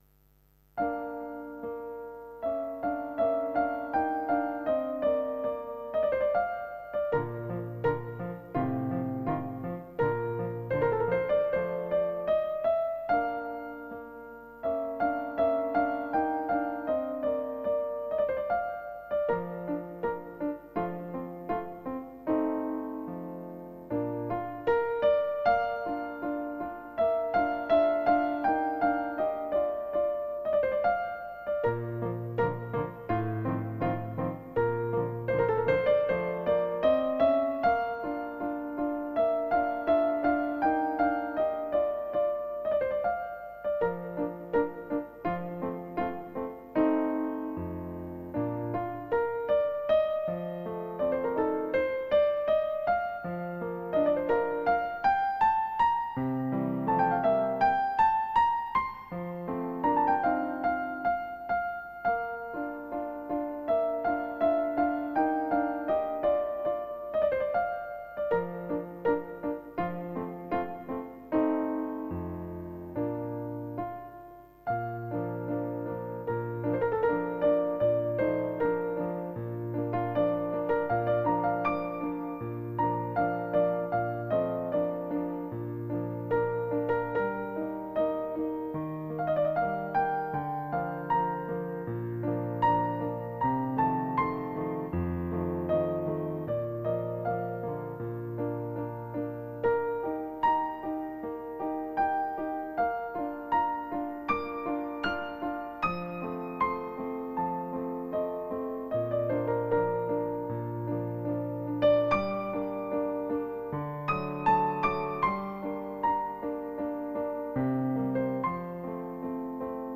Piano Concerto no. 20 in D minor, K. 466 - Romance |Mozart Effect, Concentration & Brain Power Music